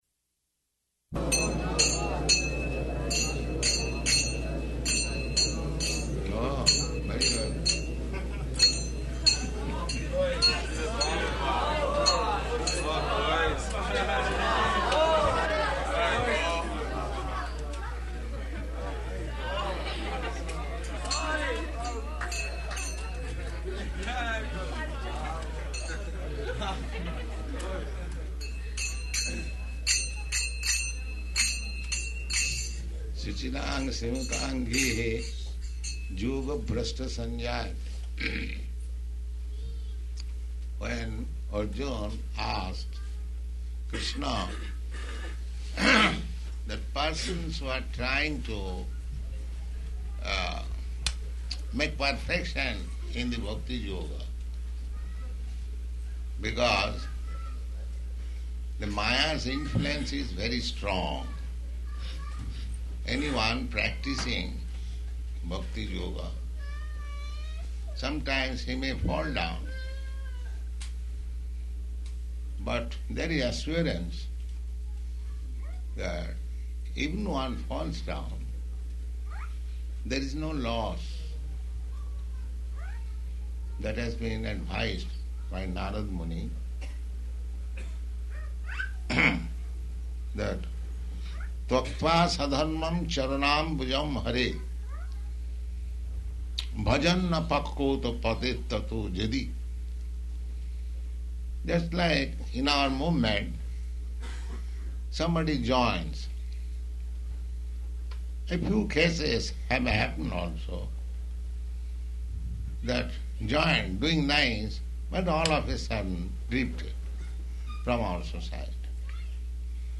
Bhagavad-gītā 6.41 --:-- --:-- Type: Bhagavad-gita Dated: July 17th 1971 Location: Detroit Audio file: 710717BG-DETROIT.mp3 [small child playing karatālas ] Prabhupāda: Oh, very good.